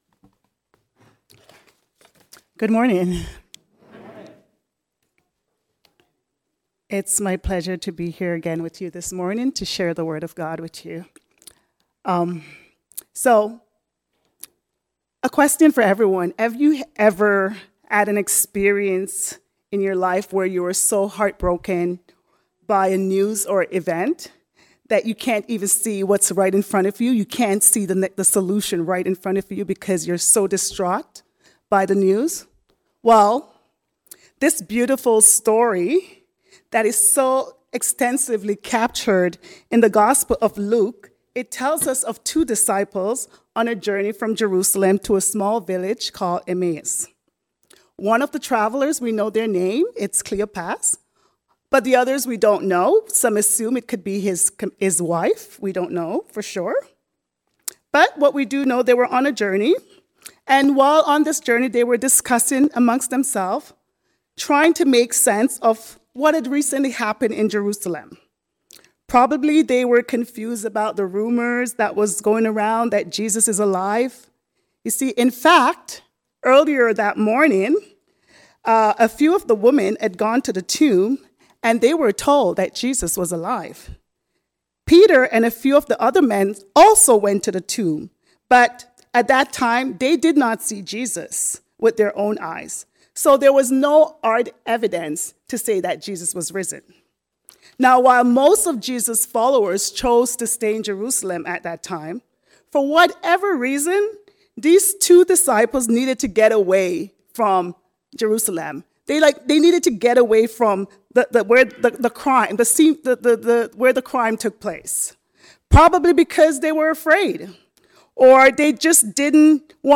Learning to see with our hearts. A sermon on Luke 24:13-35